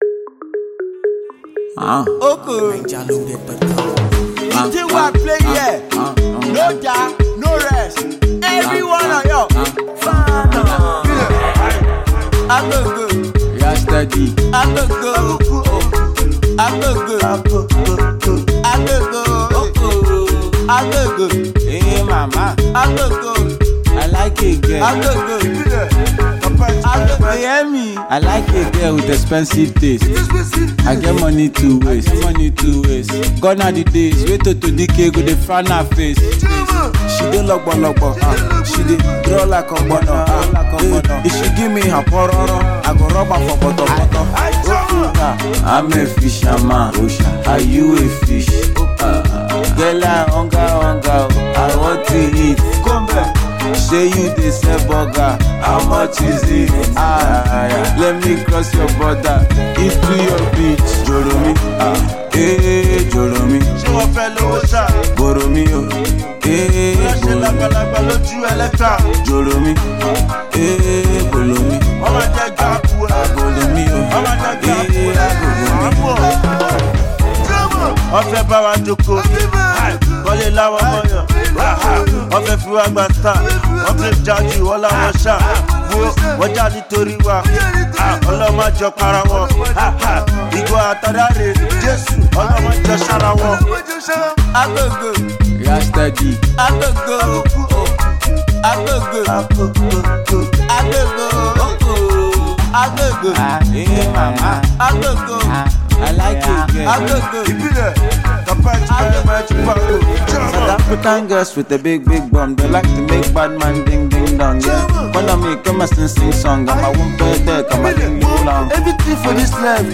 street-themed tune
a jam straight out of the streets